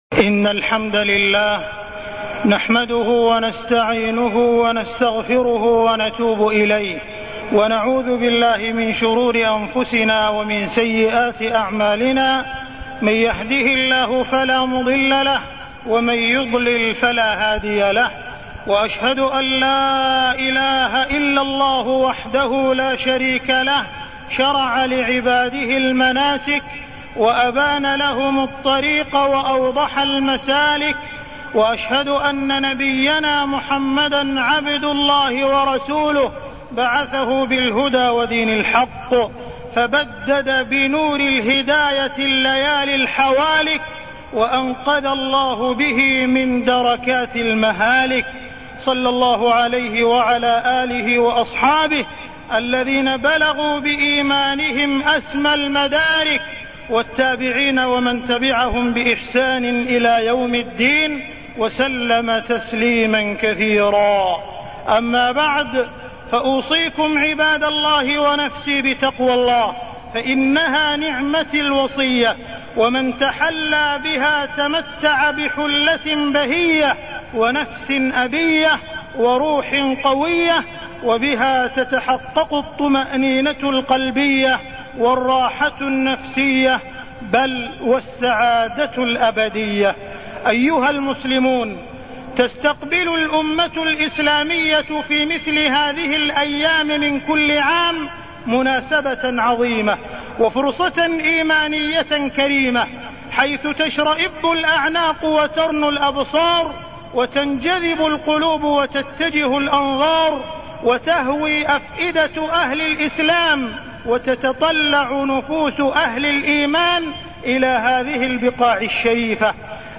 تاريخ النشر ١٩ ذو القعدة ١٤٢٠ هـ المكان: المسجد الحرام الشيخ: معالي الشيخ أ.د. عبدالرحمن بن عبدالعزيز السديس معالي الشيخ أ.د. عبدالرحمن بن عبدالعزيز السديس وأذن في الناس بالحج